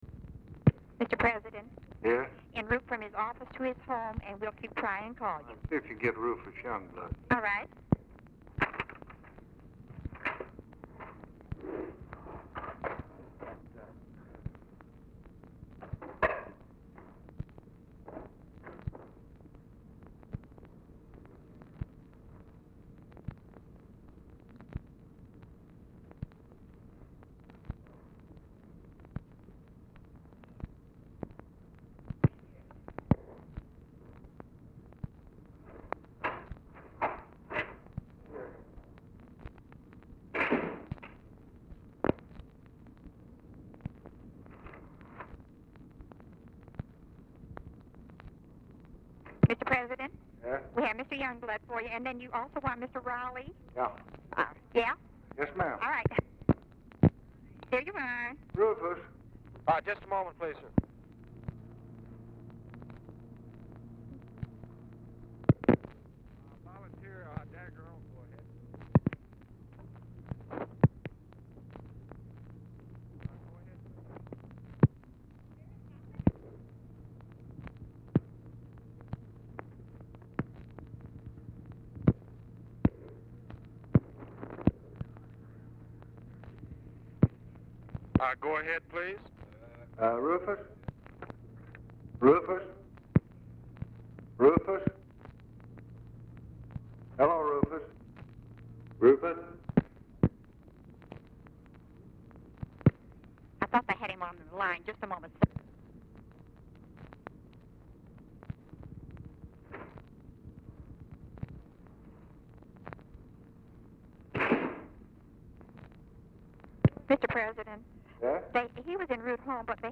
Telephone conversation # 1206, sound recording, LBJ and TELEPHONE OPERATOR, 1/6/1964, time unknown | Discover LBJ
Format Dictation belt
Location Of Speaker 1 Oval Office or unknown location
Other Speaker(s) SIGNAL CORPS OPERATOR